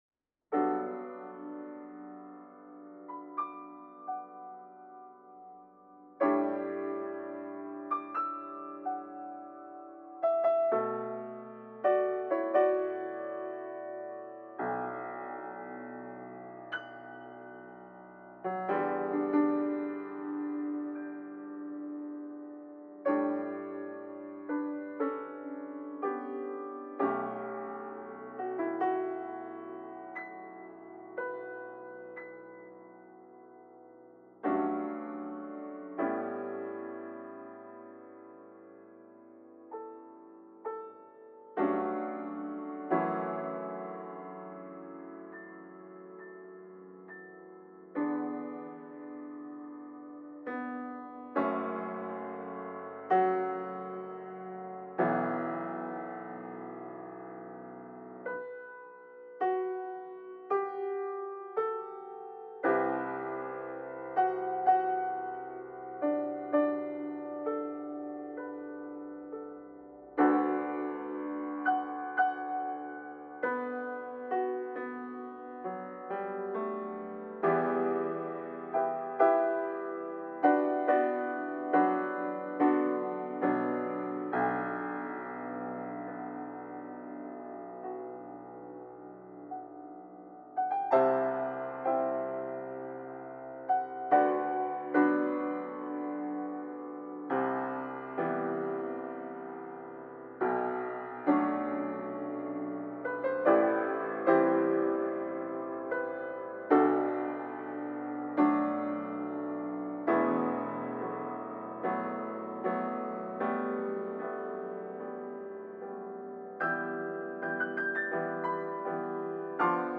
ピアノ即興 - 君の音。